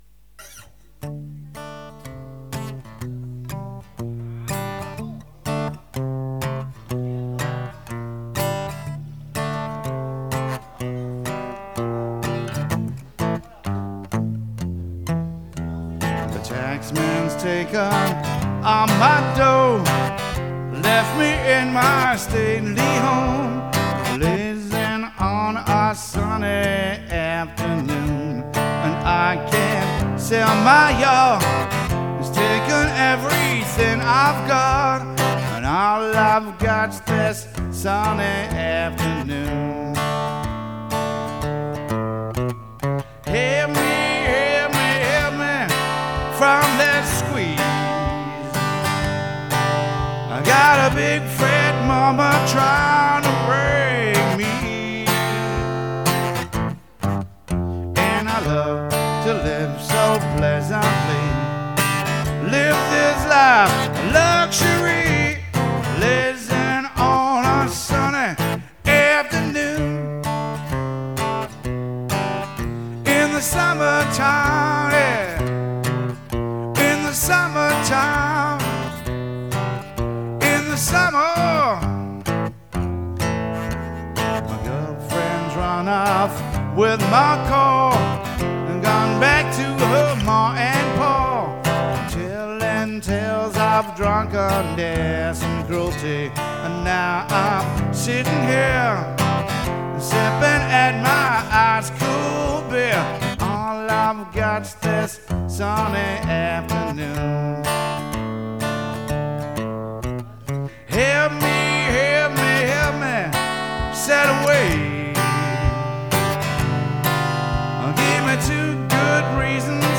"live"